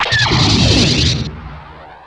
shoot2.wav